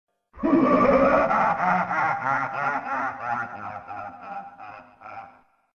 File:Master Hand Laugh(64).oga
Voice clip from Super Smash Bros.
Master_Hand_Laugh(64).oga.mp3